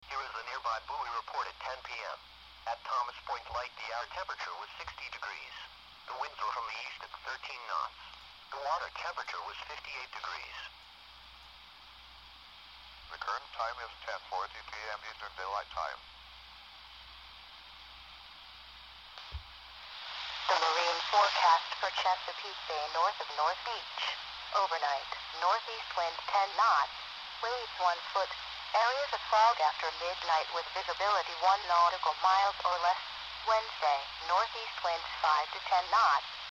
Download Cb Mic sound effect for free.
Cb Mic